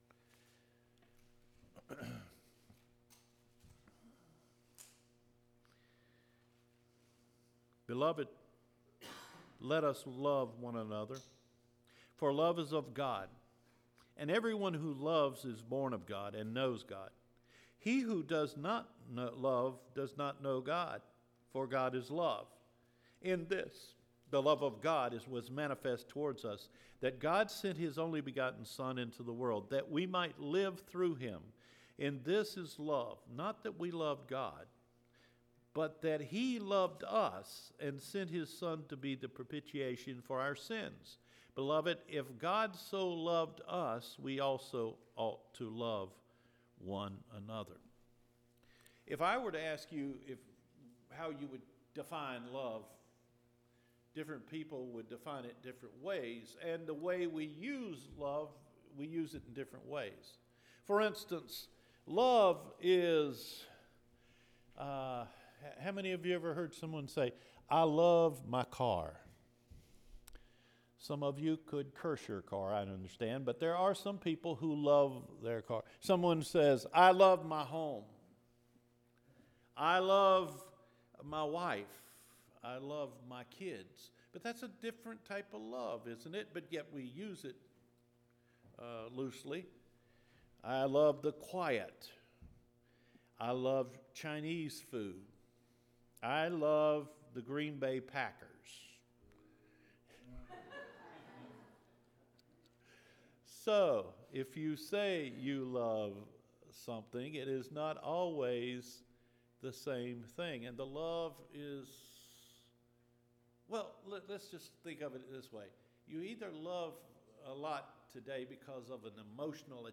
JANUARY 19 SERMON – A CHURCH FULL OF LOVE, STEPPING INTO THE PRESENCE OF GOD